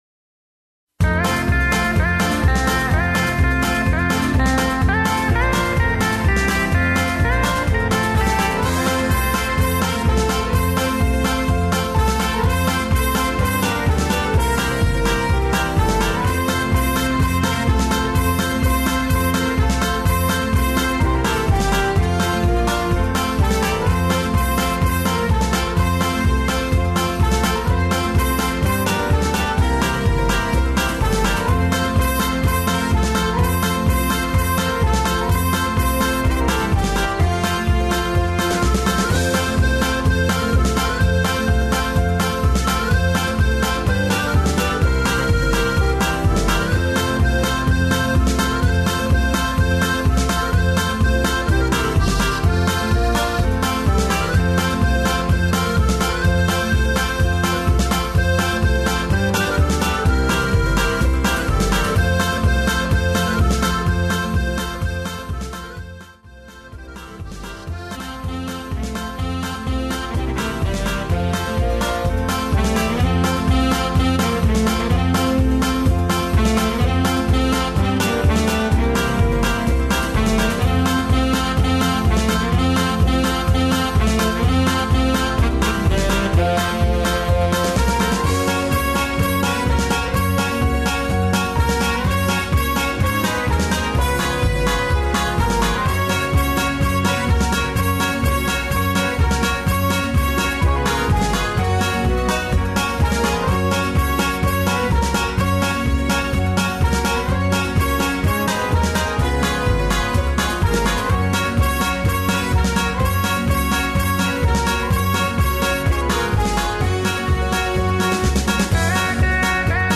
Spiritual Music